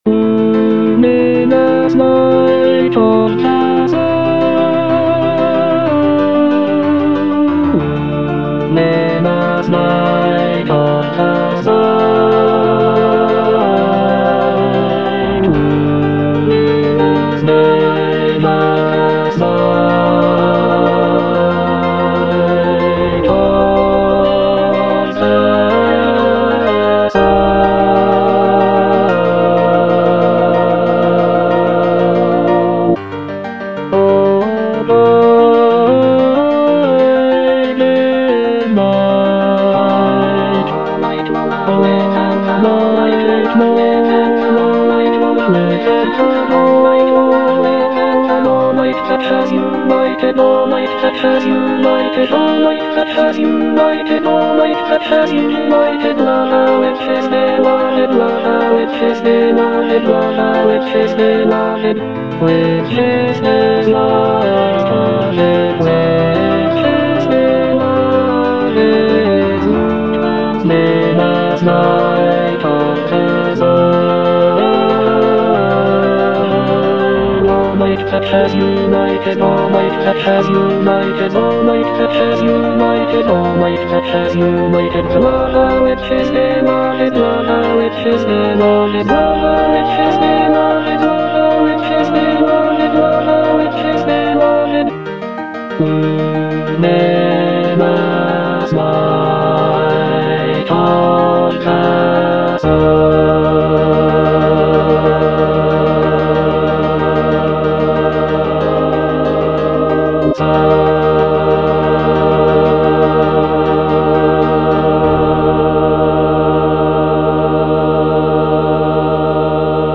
(bass I) (Emphasised voice and other voices) Ads stop
choral work